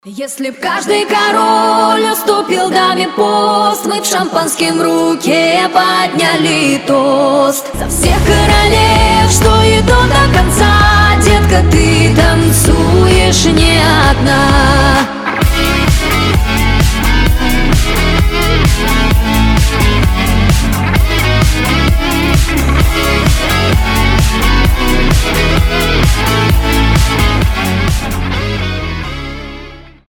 • Качество: 320, Stereo
гитара
Cover
Electropop
воодушевляющие